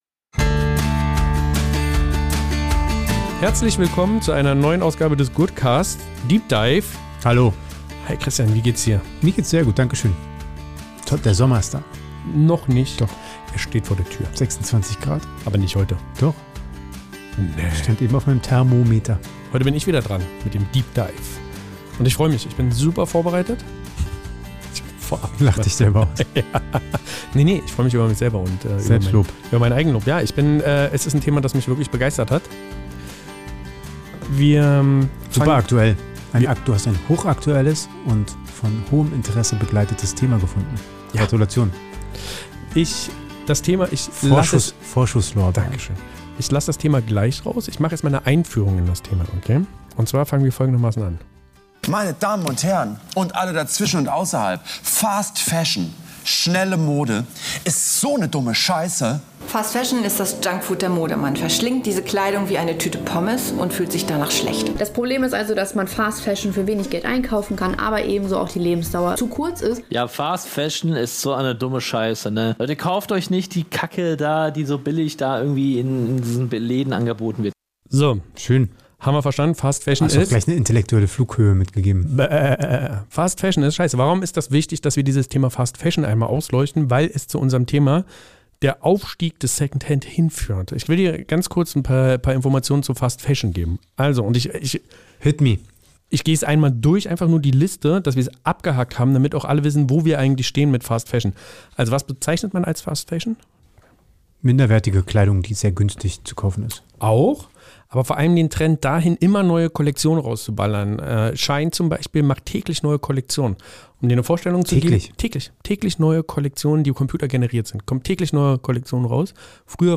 Im Interview erzählt er, wie aus alten Stoffen neue Lieblingsstücke werden und warum textile Kreisläufe dringend mehr Aufmerksamkeit brauchen.